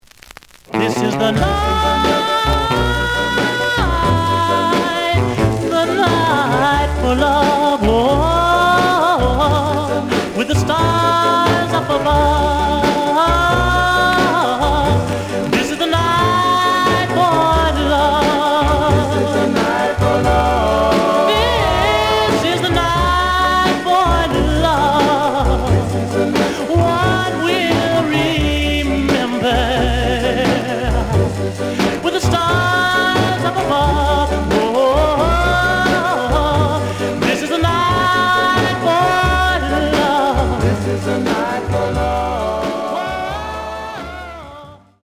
The audio sample is recorded from the actual item.
●Format: 7 inch
●Genre: Rhythm And Blues / Rock 'n' Roll
Some click noise on middle of A side due to scratches.